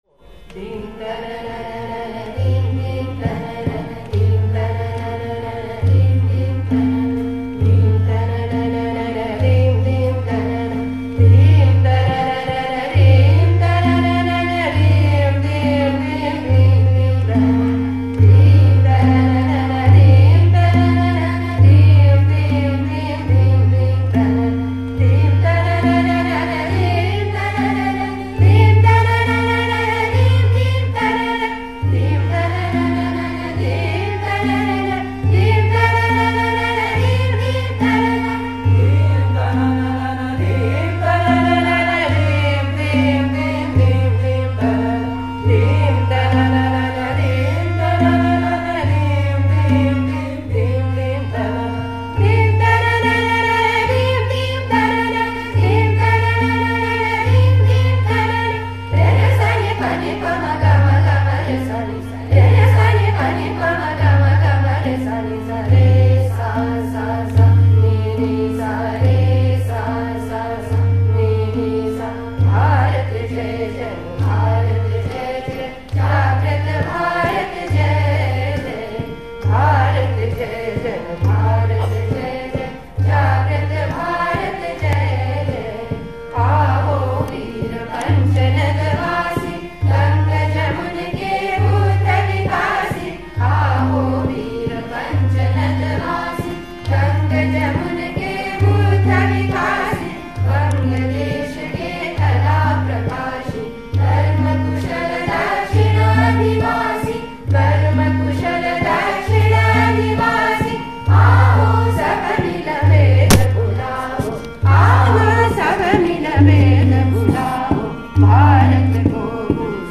Singing classes
They presented the three songs they had learned before a campus audience on April 16th, 2009